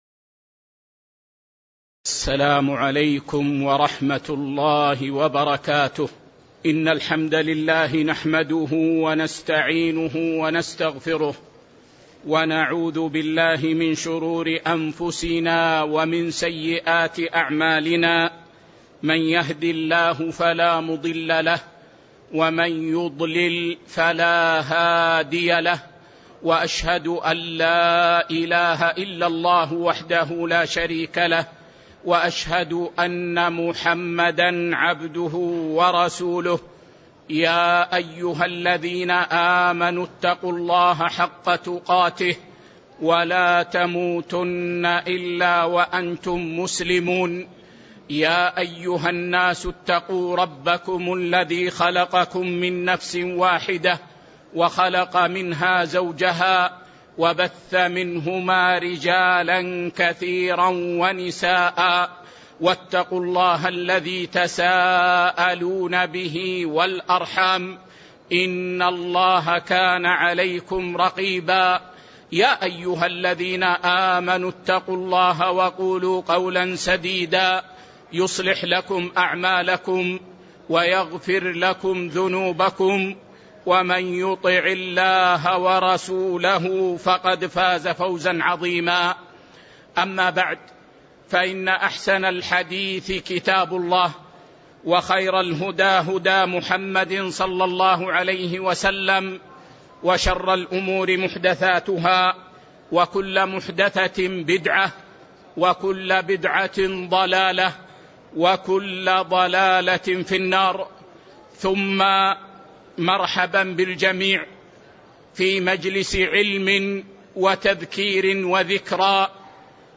تاريخ النشر ٩ ربيع الثاني ١٤٤٤ هـ المكان: المسجد النبوي الشيخ